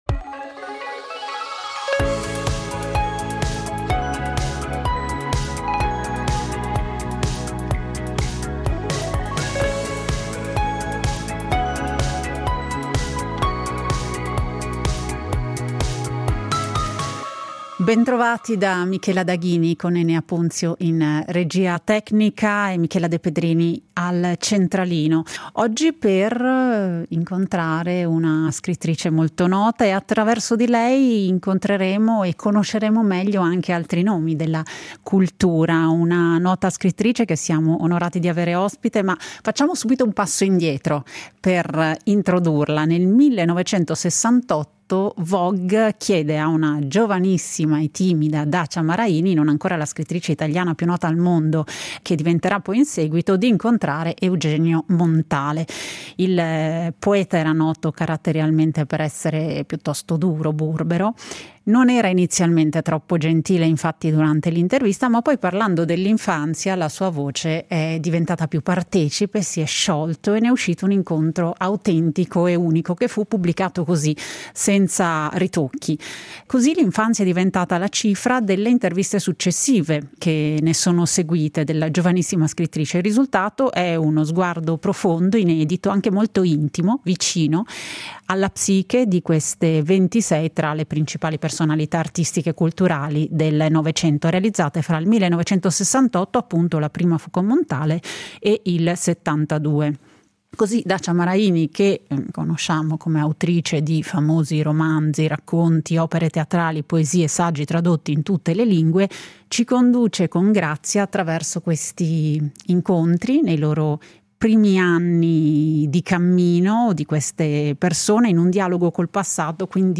La magia dell’infanzia. A colloquio con Dacia Maraini